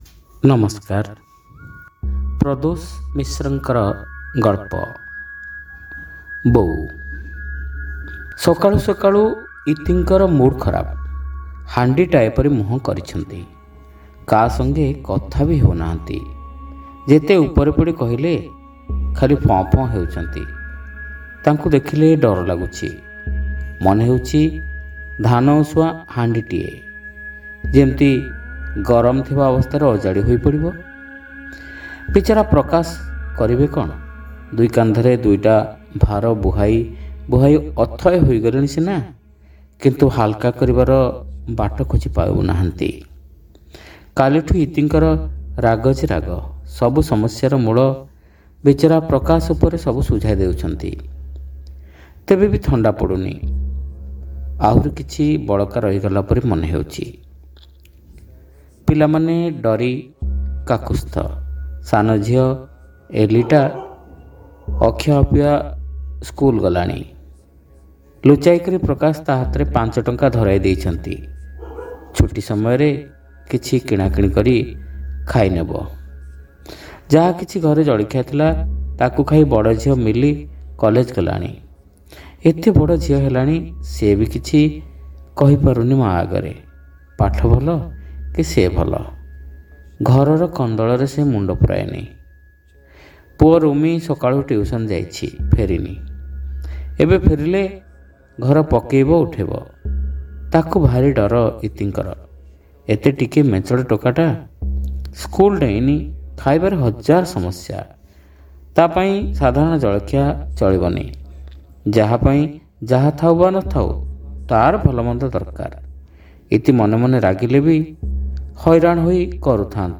ଶ୍ରାବ୍ୟ ଗଳ୍ପ : ବୋଉ (ପ୍ରଥମ ଭାଗ)